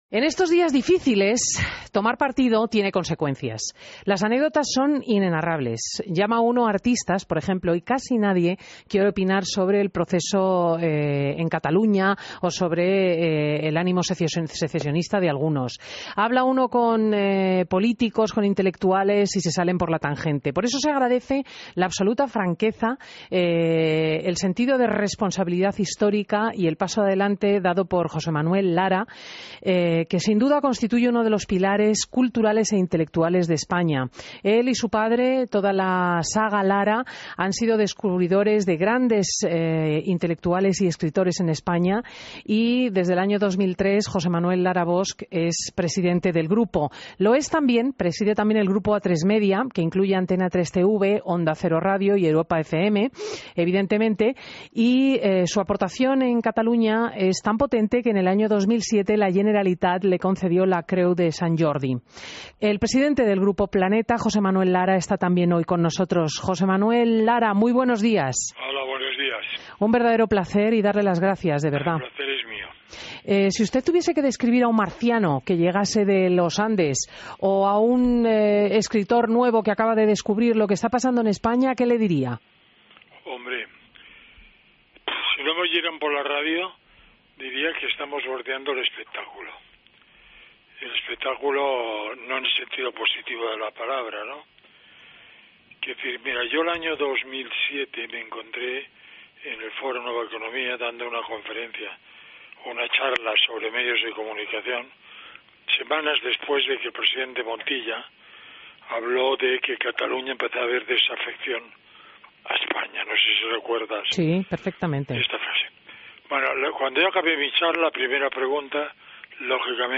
AUDIO: Entrevista a José Manuel Lara en Fin de Semana COPE